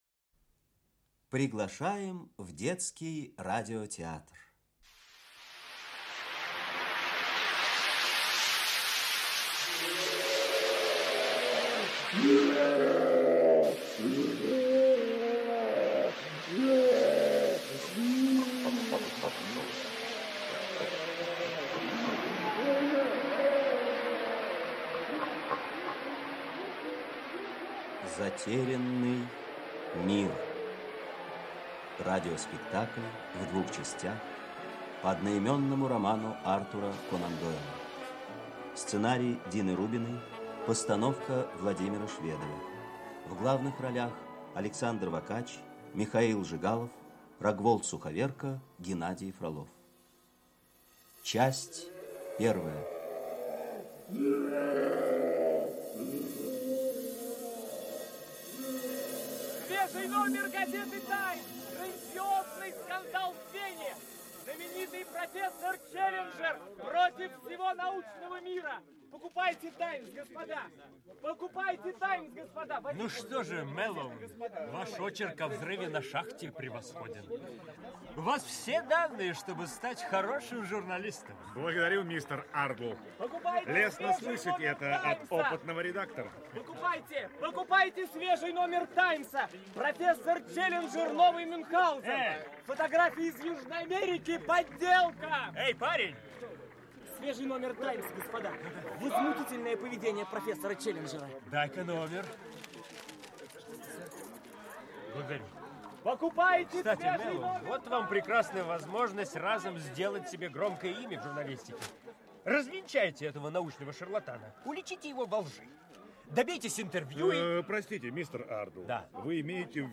Аудиокнига Затерянный мир (спектакль) | Библиотека аудиокниг
Прослушать и бесплатно скачать фрагмент аудиокниги